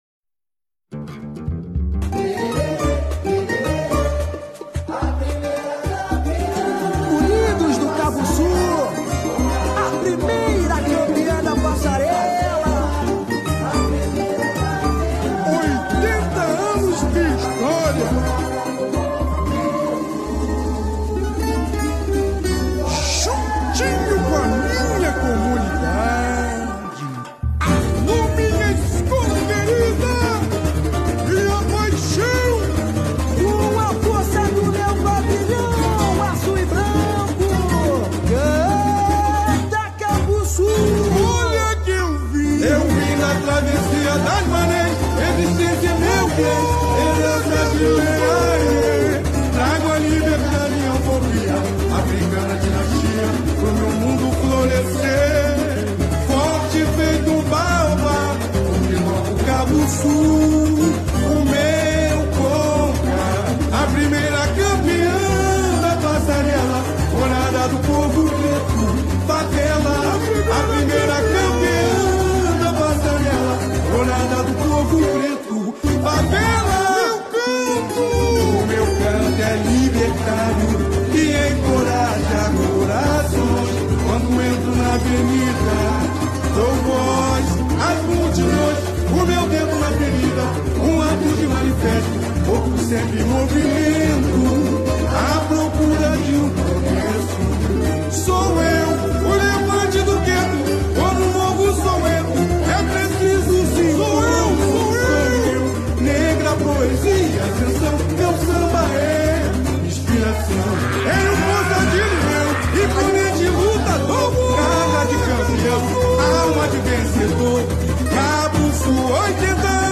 A Cabuçu definiu o seu samba-enredo oficial para o carnaval de 2025.